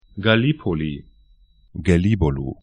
Gallipoli ga'li:poli Gelibolu gɛ'li:bɔlu tr Stadt / town 40°24'N, 26°40'E